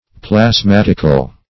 Search Result for " plasmatical" : The Collaborative International Dictionary of English v.0.48: Plasmatic \Plas*mat"ic\, Plasmatical \Plas*mat"ic*al\, a. [Gr.